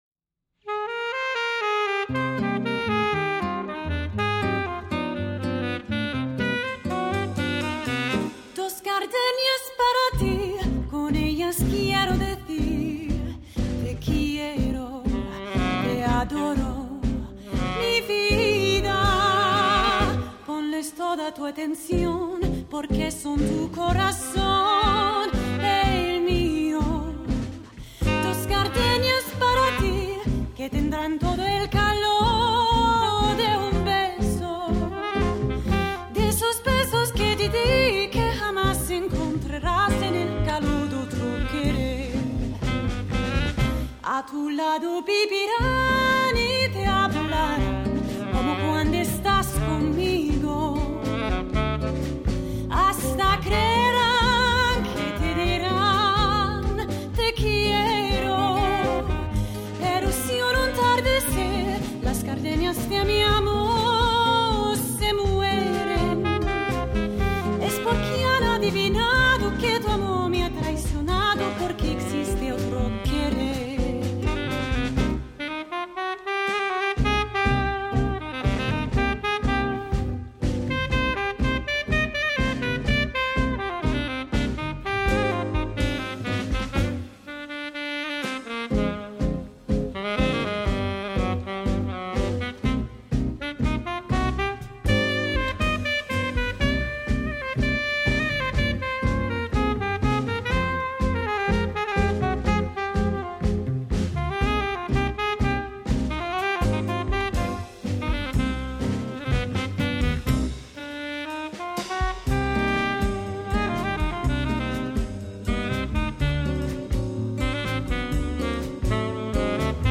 vintage swing and Latin jazz band based in London.